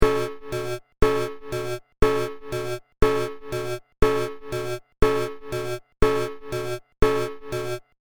警告。